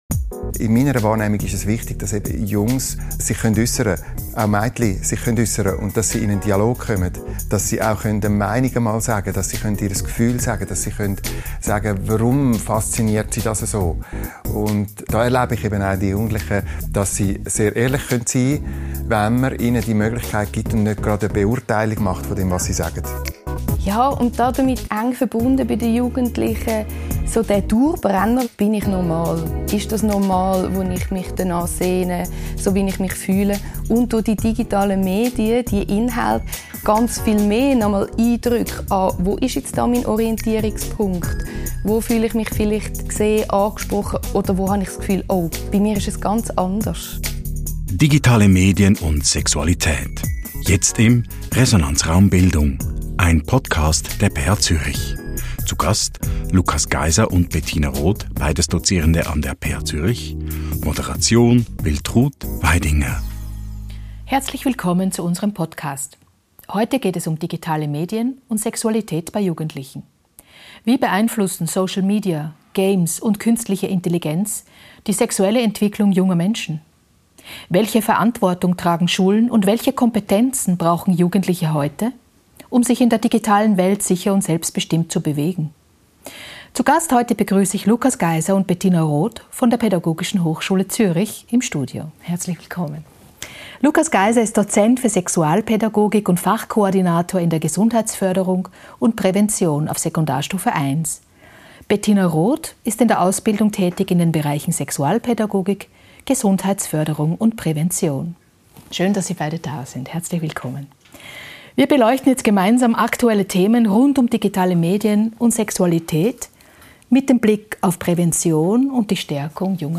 Moderiert wurde das Gespräch